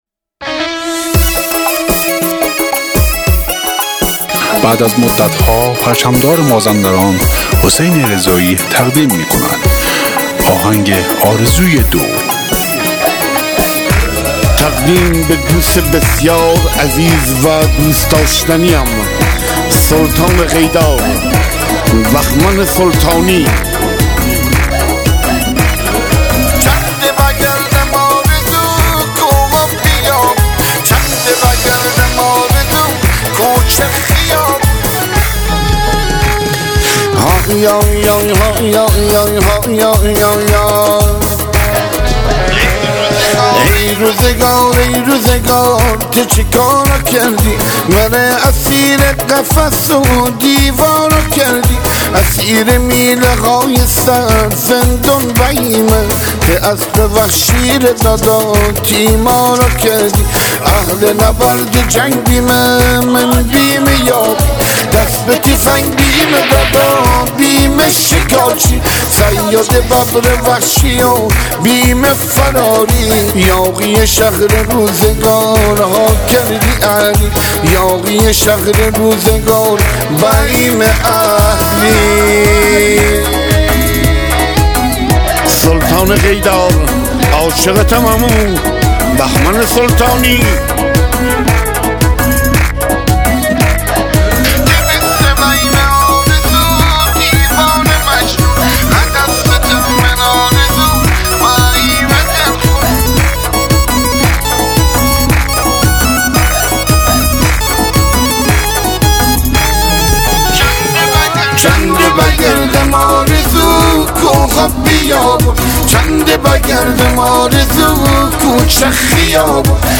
محلی مازندرانی